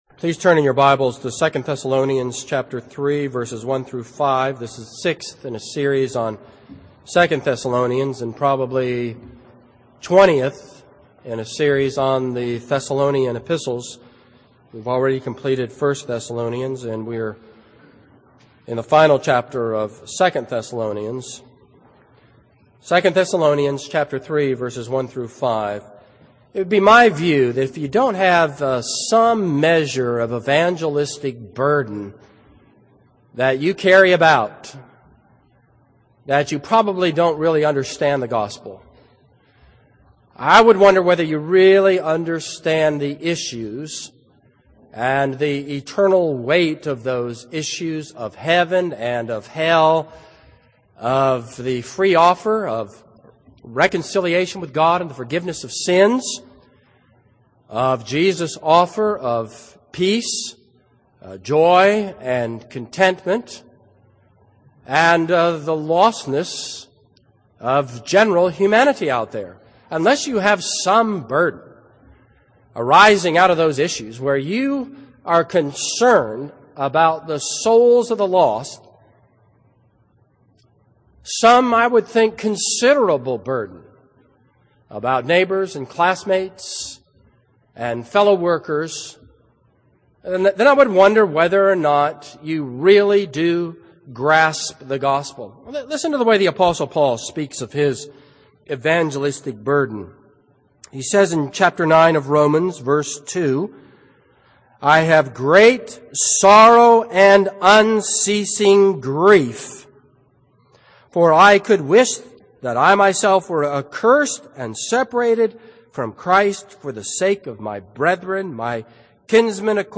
This is a sermon on 2 Thessalonians 3:1-5.